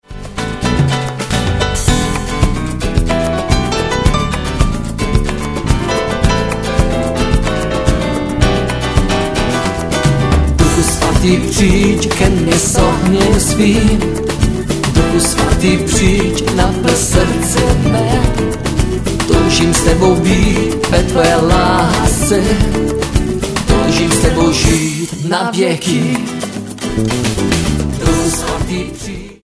Sborový zpěv: